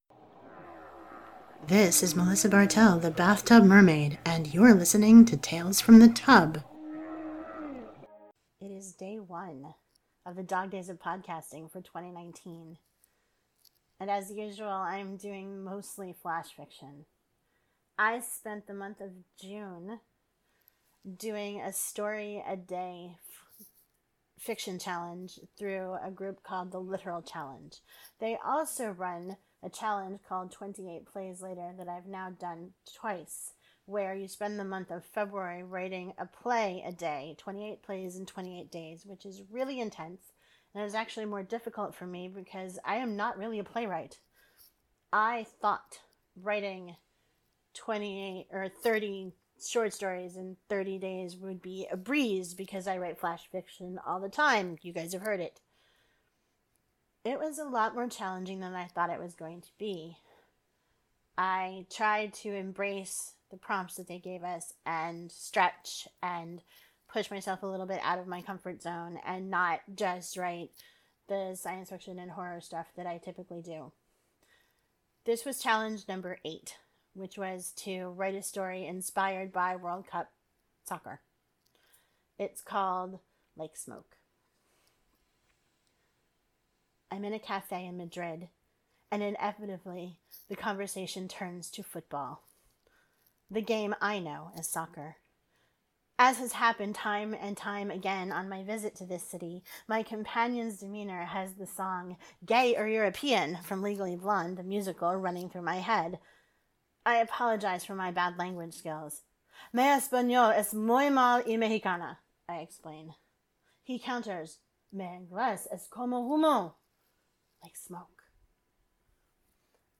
• Music used for the opening and closing is a mix of Chris Zabriskie’s “The Oceans Continue to Rise” from the Free Music Archive and Kevoy’s clip of whales off the coast of French Polynesia from Freesound.
• Chris Zabriskie’s song is also used under some readings.